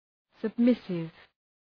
submissive.mp3